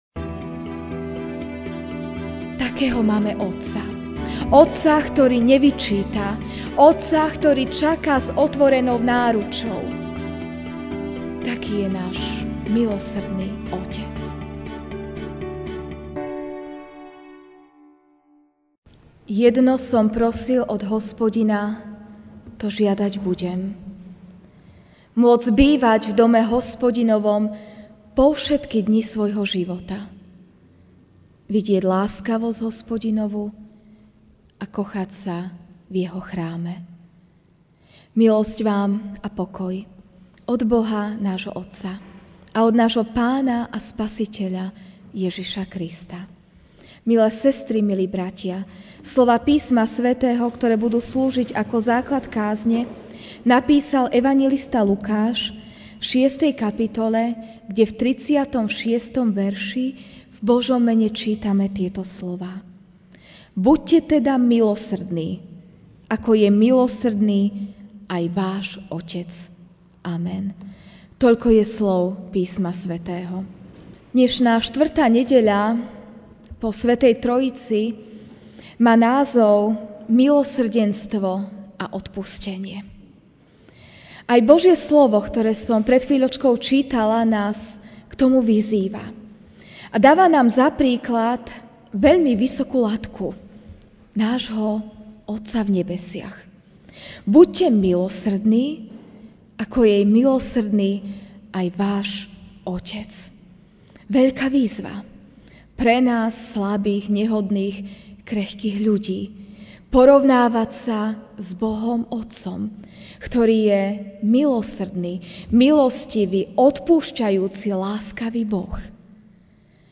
júl 14, 2019 Odpúšťajúci Nebeský Otec MP3 SUBSCRIBE on iTunes(Podcast) Notes Sermons in this Series Večerná kázeň: Odpúšťajúci Nebeský Otec (L 6, 36) Buďte teda milosrdní, ako je milosrdný aj váš Otec.